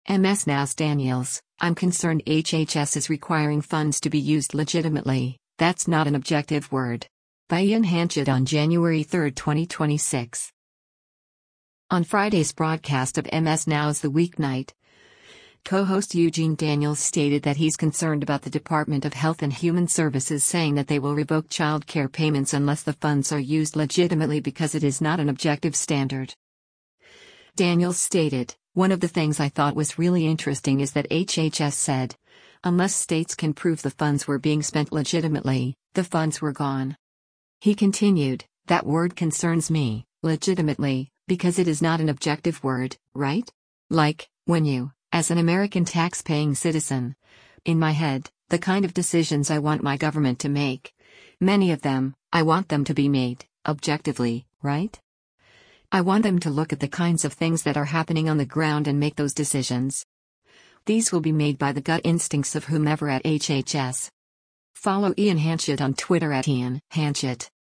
On Friday’s broadcast of MS NOW’s “The Weeknight,” co-host Eugene Daniels stated that he’s concerned about the Department of Health and Human Services saying that they will revoke childcare payments unless the funds are used “legitimately” because “it is not an objective” standard.